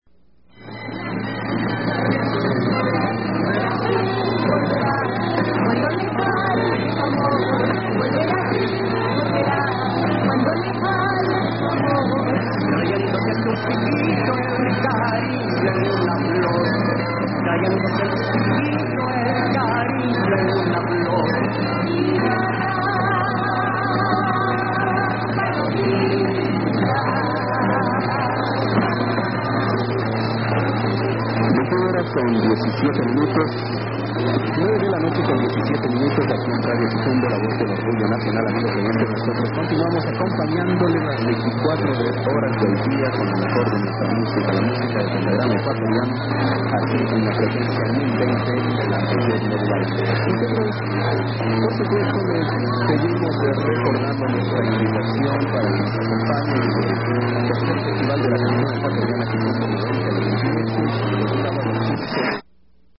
Almost certainly Ecuadorian, but ID doesn't sound like anything listed.